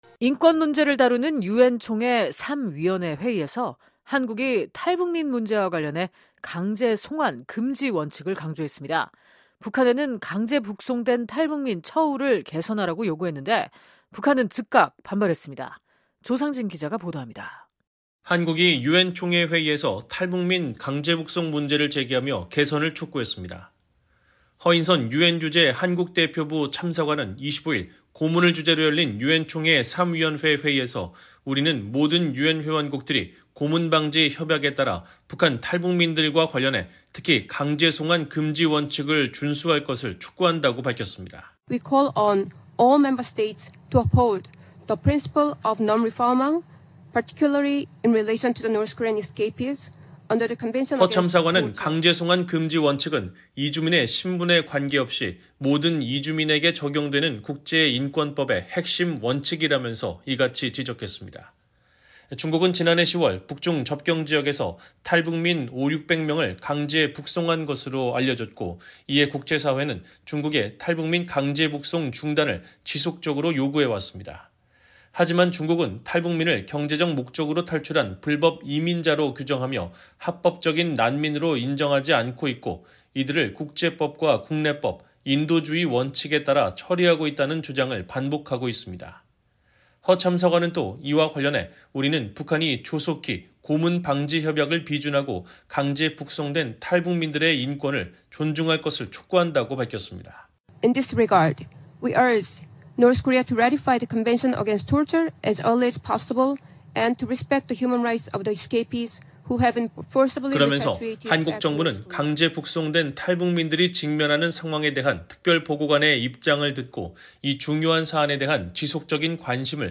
유엔총회 제3위원회 회의장